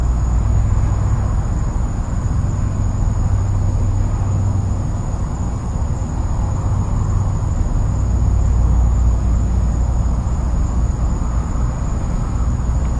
生物 " 青蛙2
描述：用奥林巴斯DS40与索尼ECMDS70P记录的夜间的青蛙和昆虫。
Tag: 场记录 青蛙 昆虫